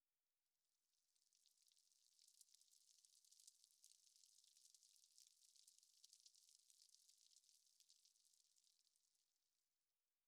环境音
06_公寓楼道_灯.wav